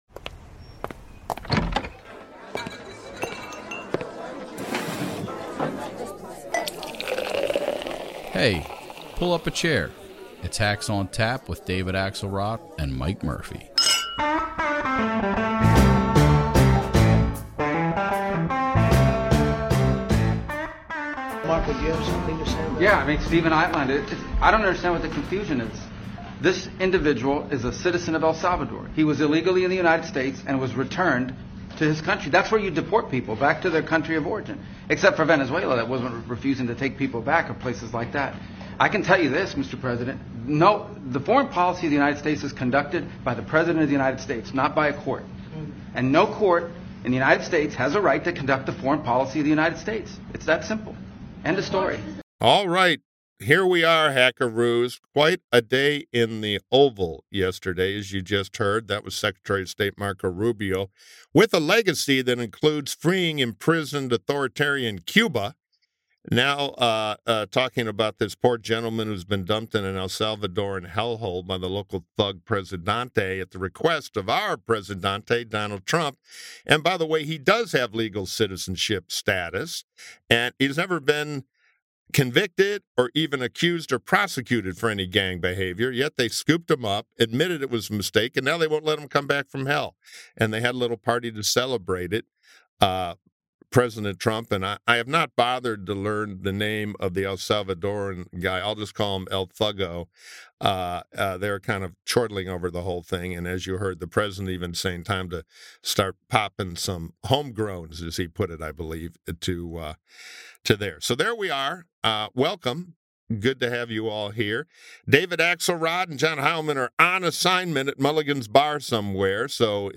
This week, Mike Murphy is flying solo — but fear not, he’s joined by two heavyweight Hacks: Jonathan Martin, veteran political reporter and senior political columnist for Politico, and Robert Costa, CBS News chief election and campaign correspondent and co-author of Peril with Bob Woodward. Together, they dive into POTUS v. SCOTUS, wrongful deportations, China’s tariff triumphs, Harvard fighting back, deficits, stagflation, strongman stuff, and — naturally — a slew of other uplifting developments in American democracy.